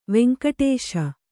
♪ veŋkaṭ`śa